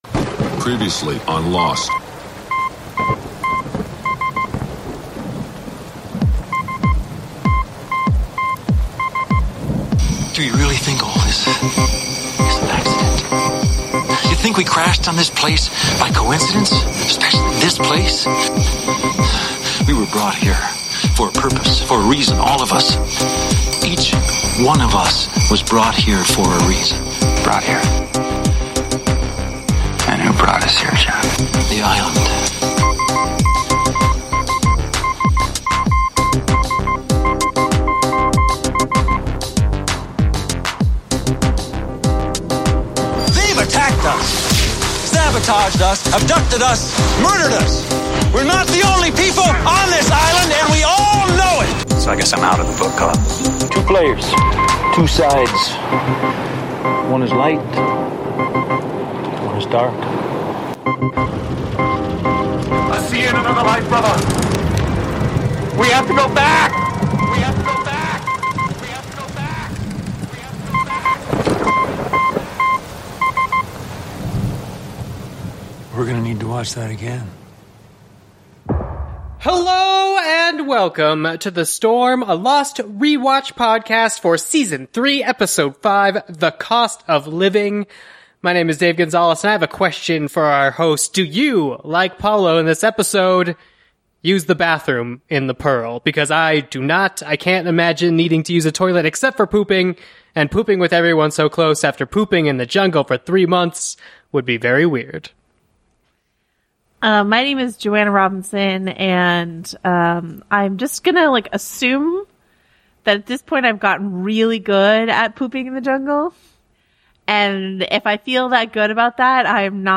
THE INTERVIEW (No Spoilers!)